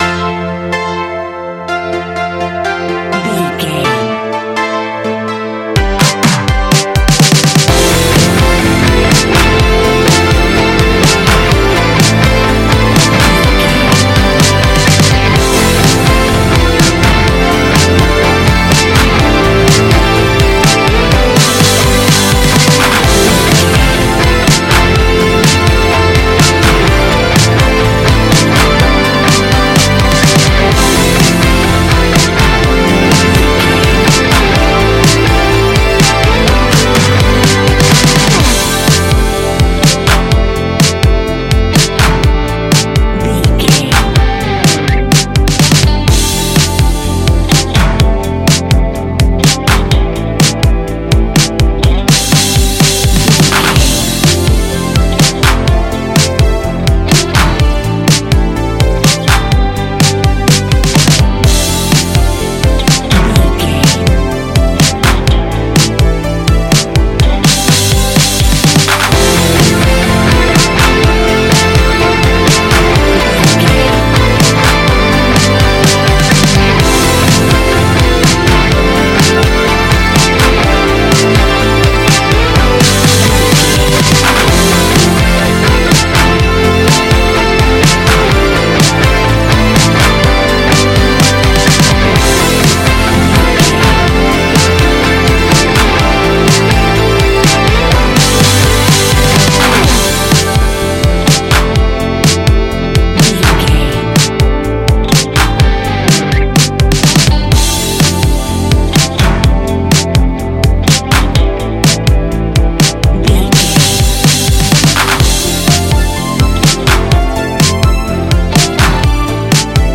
Ionian/Major
ambient
new age
chill out
downtempo
synth
pads